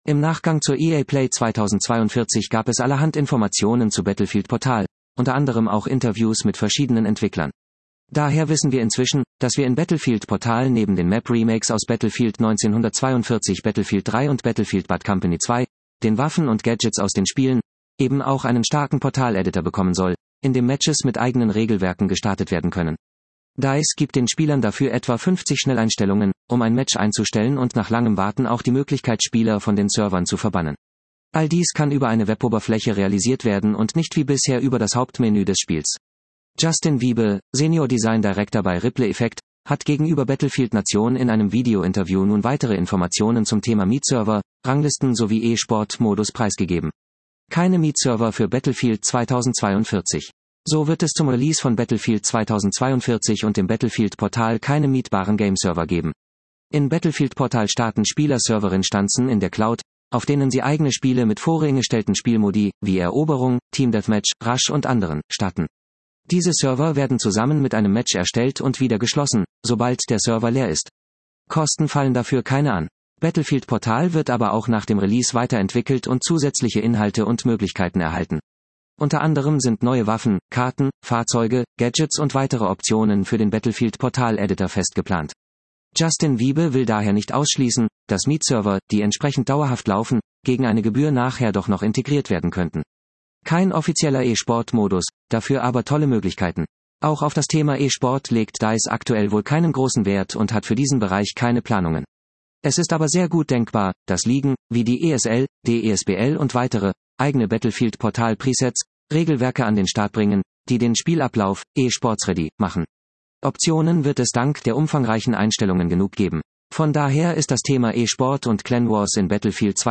Zu guter Letzt wird über Ranglisten Spiele bzw. Ranked Matches gesprochen.